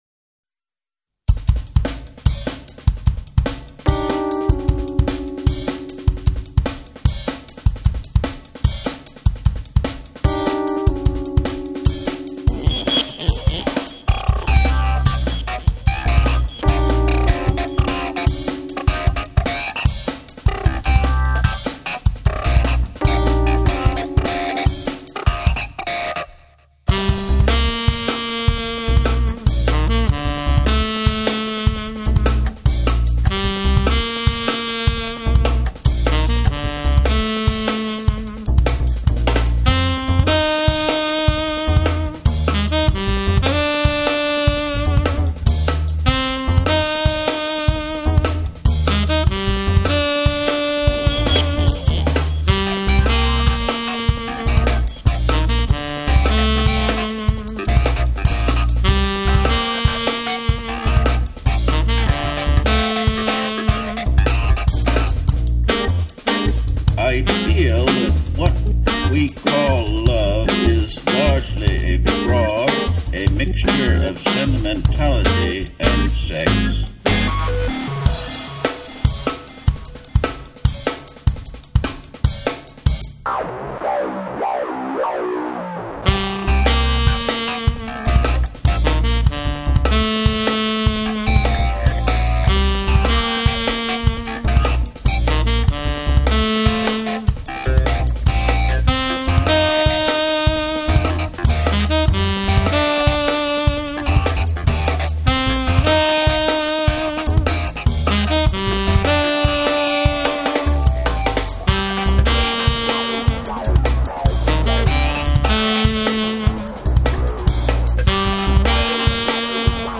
sax tenore
Tastiere
Basso
Batteria